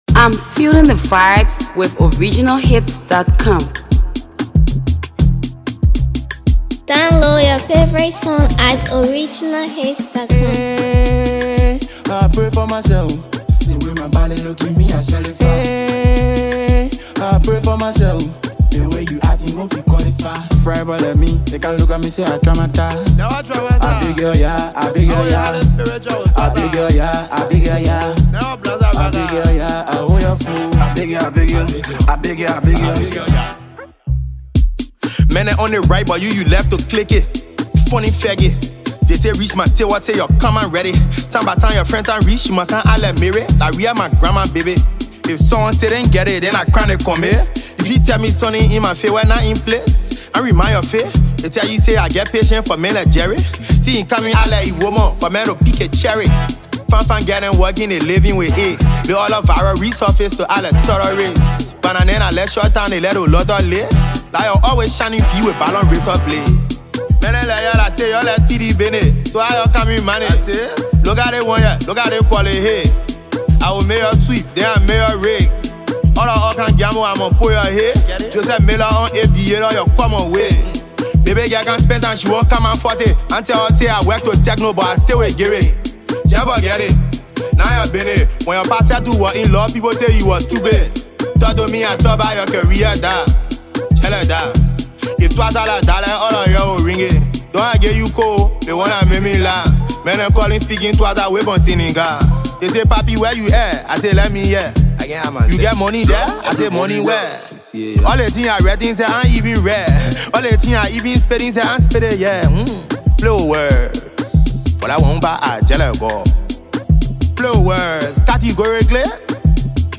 Sensational trap artist
hardcore lyrical anthem
blends hard-hitting beats with compelling lyrics